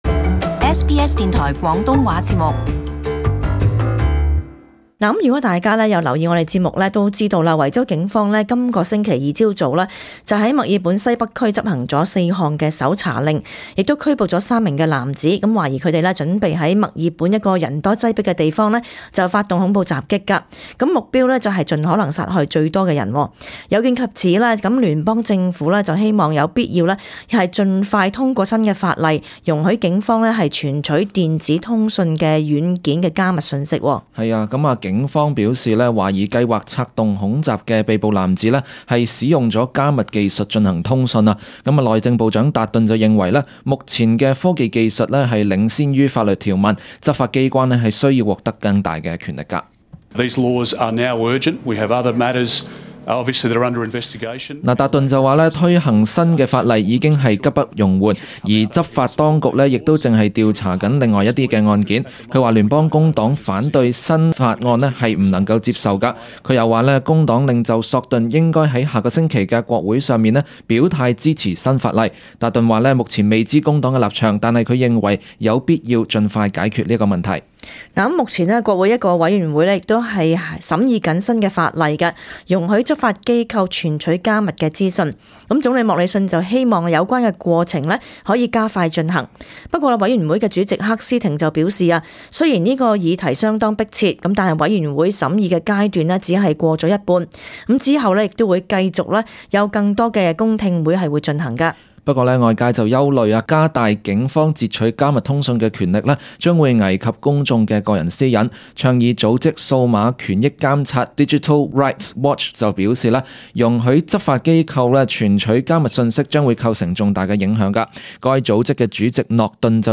【時事報導】政府欲盡快修改加密訊息法例